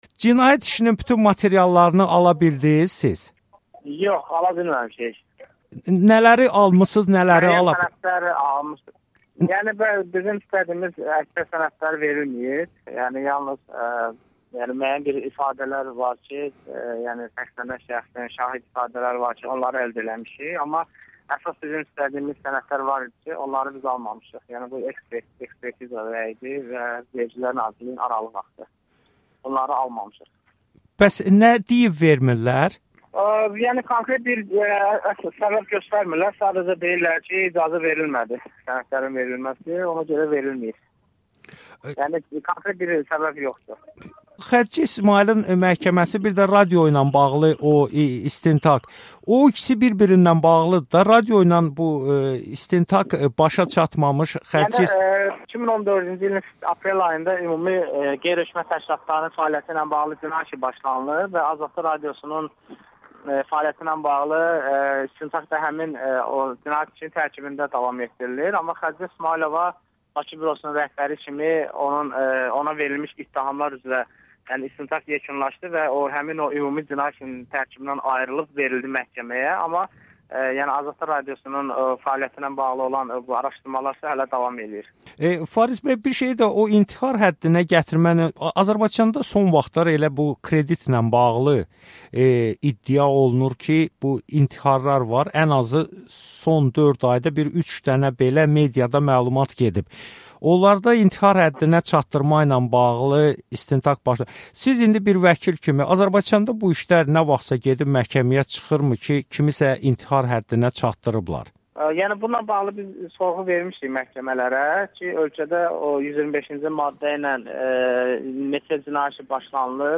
Araşdırmaçı jurnalist Xədicə İsmayılın cinayət işi üzrə məhkəmənin hazırlıq iclası iyulun 24-nə təyin olunub. Məhkəmə ərəfəsində AzadlıqRadiosunun suallarını vəkil